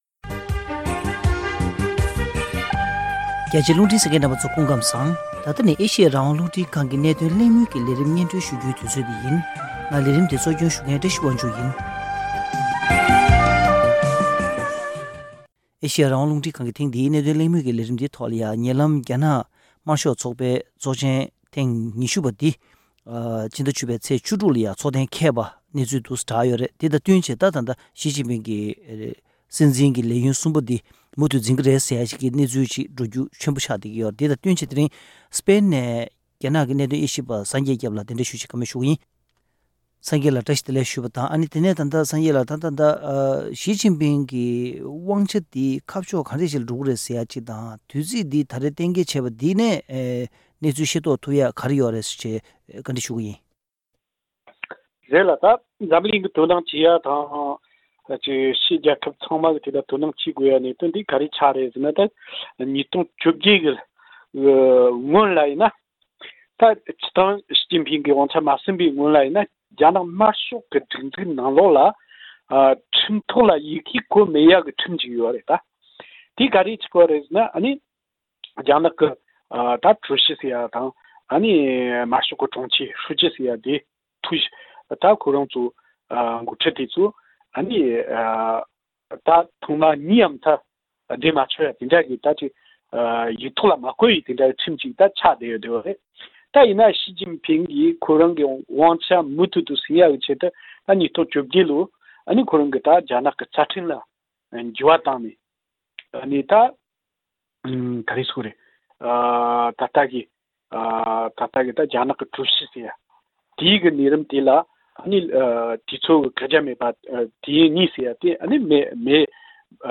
རྒྱ་ནག་དམར་ཤོག་ཚོགས་པའི་འཐུས་མིའི་ཚོགས་ཆེན་ཐེངས་ ༢༠ པ་དང་ཞི་ཅིན་ཕིང་གི་སྲིད་འཛིན་ལས་ཡུན་སོགས་དང་འབྲེལ་བའི་སྐོར་གནད་དོན་དབྱེ་ཞིབ་པ་དང་གླེང་མོལ་ཞུས་པ།